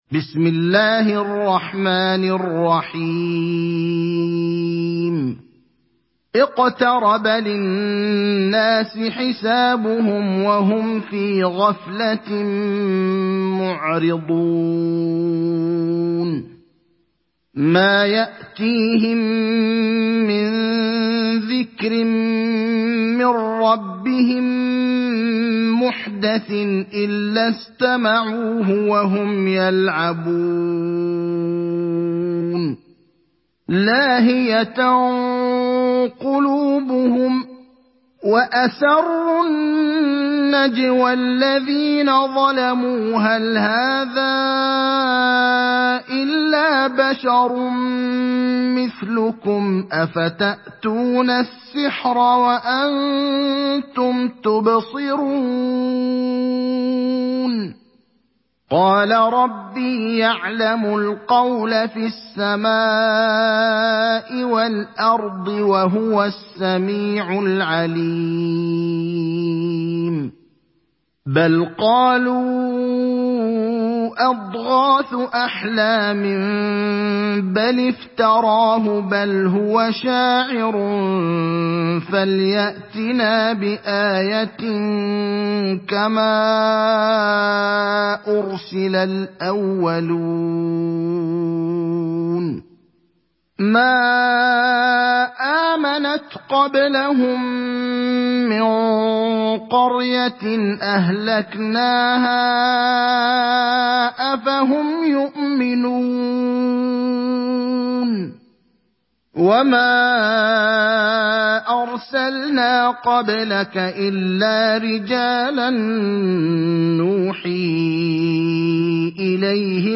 সূরা আল-আম্বিয়া ডাউনলোড mp3 Ibrahim Al Akhdar উপন্যাস Hafs থেকে Asim, ডাউনলোড করুন এবং কুরআন শুনুন mp3 সম্পূর্ণ সরাসরি লিঙ্ক